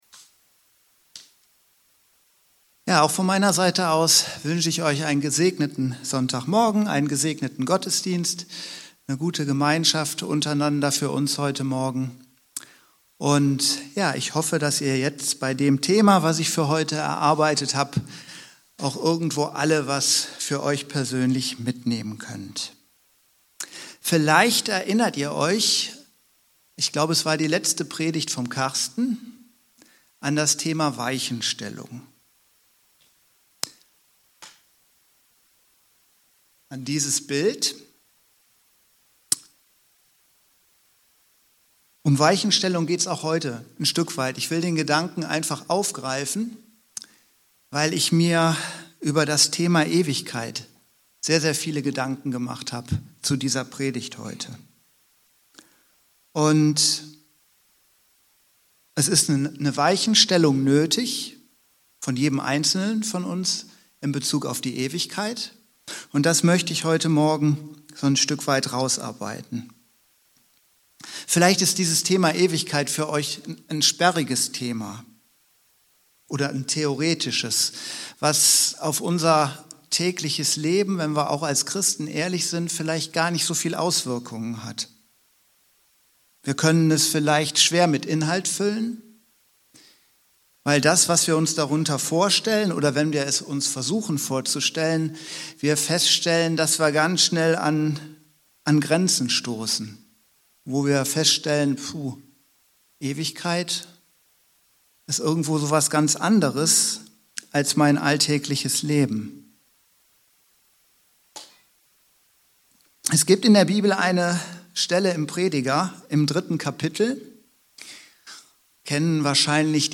Herzlich willkommen in unserer Mediathek Hier finden sich die unterschiedlichsten Predigten und Vorträge, die im Laufe der letzten Monate und Jahre von verschiedenen Predigern und Laienpredigern gehalten wurden.